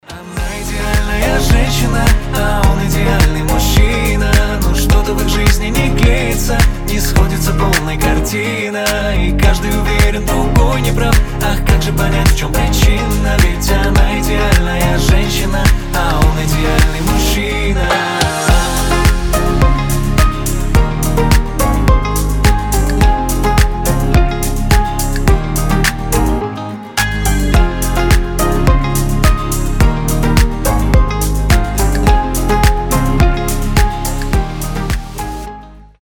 • Качество: 320, Stereo
tropical house